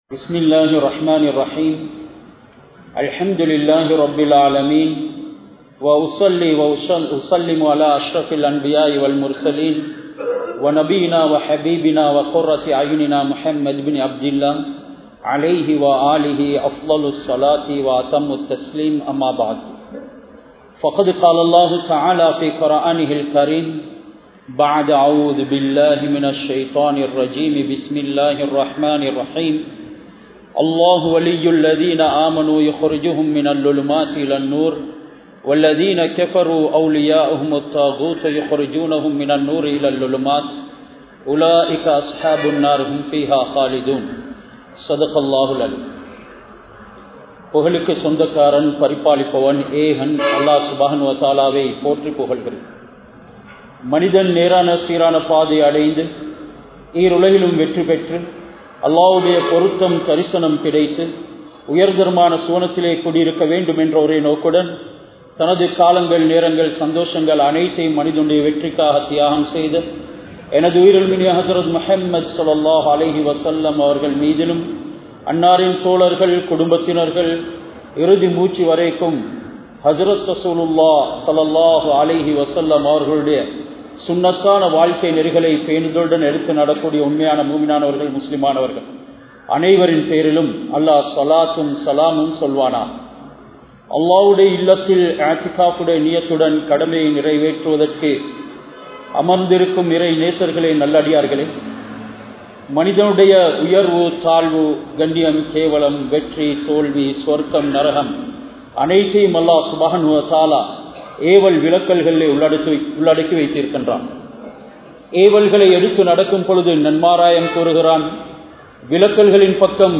Welimada, Town Jumua Masjidh